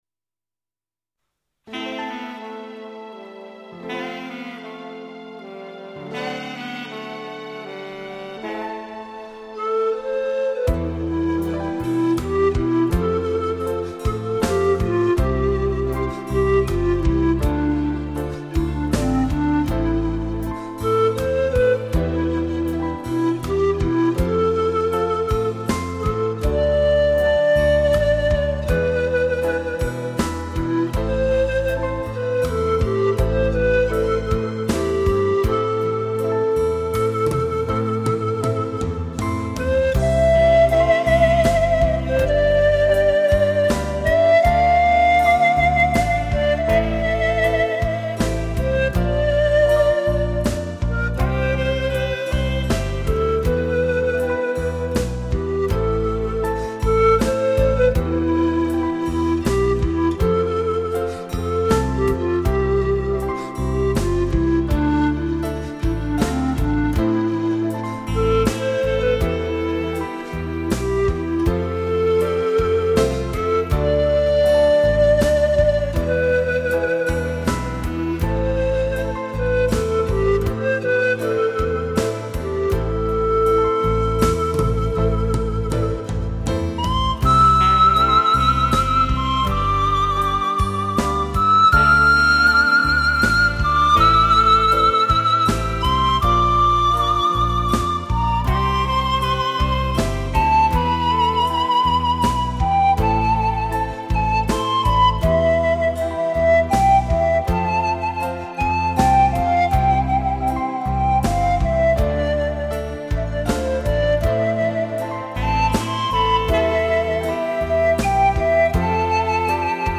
那略带忧伤、飘逸的旋律。